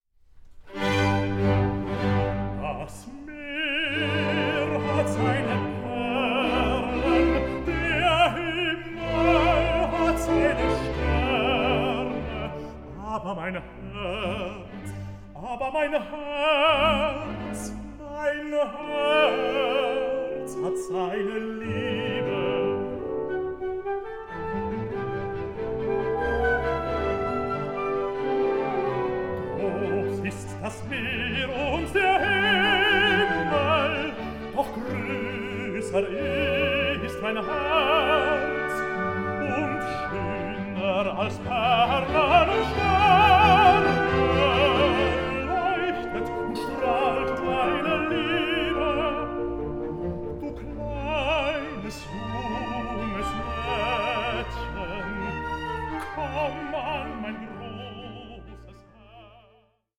Songs for voice and orchestra
tenor